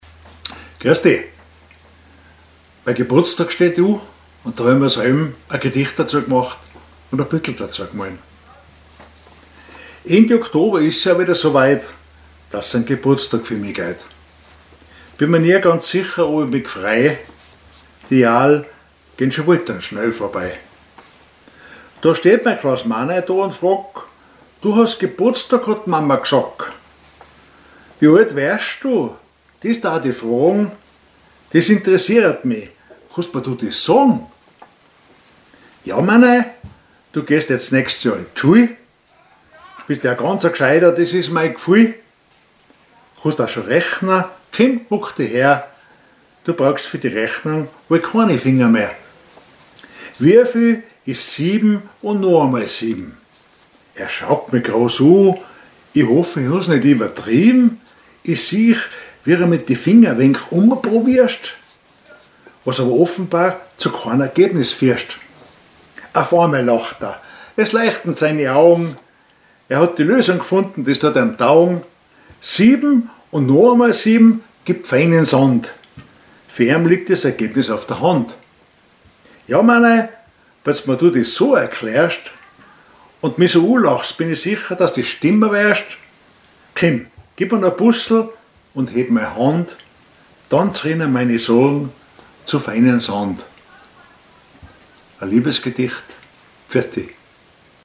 Type: Liebesgedicht